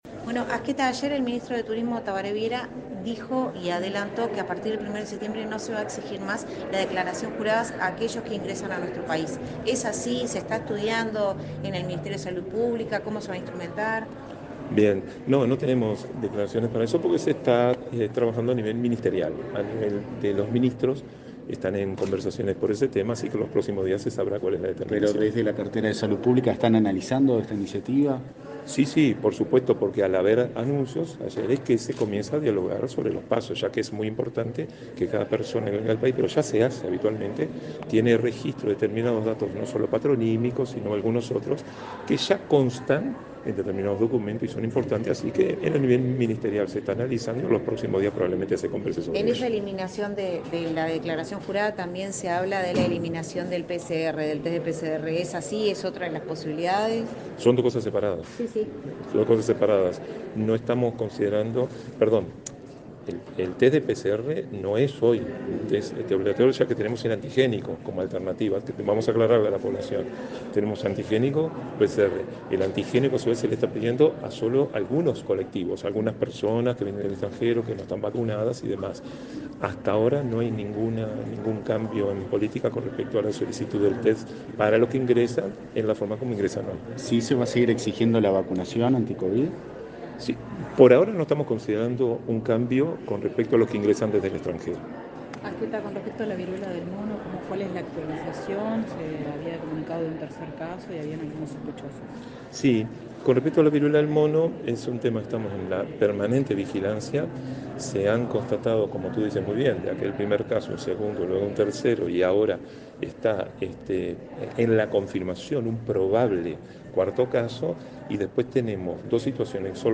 Declaraciones del director de Salud del MSP
Luego, dialogó con la prensa.